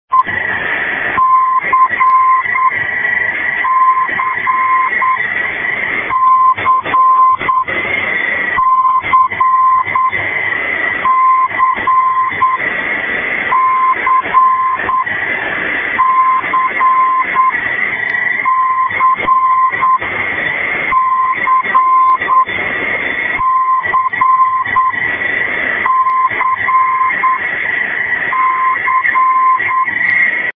Designated as "MX" by Enigma 2000, these are single letter beacons which are used by the Russian navy to test the propagation.
Best reception is in CW mode, if your reciever doesen't have CW mode SSB works just as well.
Beacon_S_5.153.mp3